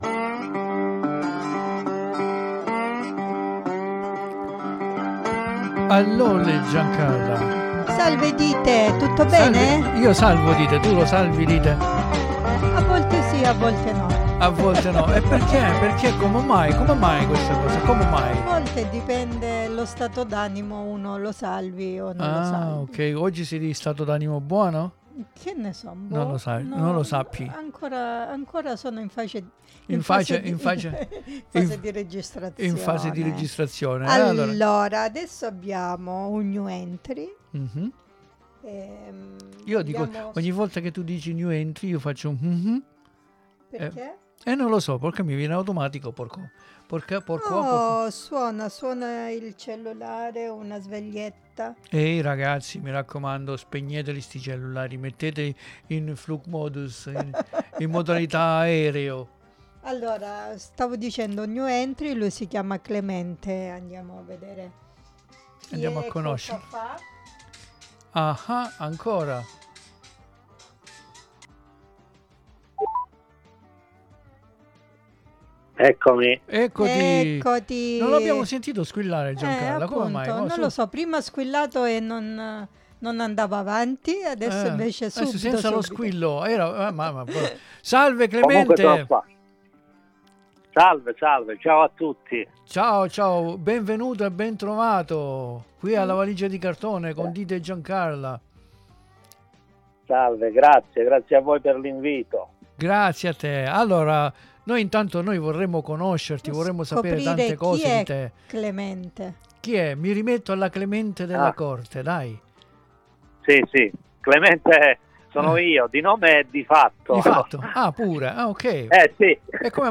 VI AUGURO UN BUON ASCOLTO CON L'INTERVISTA CONDIVISA QUI IN DESCRIZIONE.